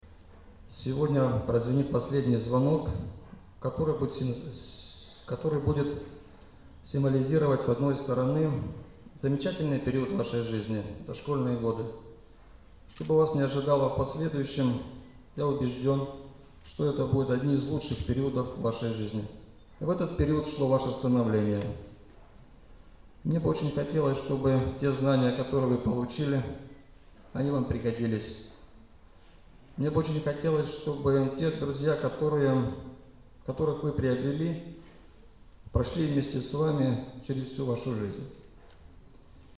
Кроме того, с напутственными словами к школьникам обратился заместитель Города Вологды Вадим Цепа.
Вадим Цепа обратился к выпускникам с напутственным словом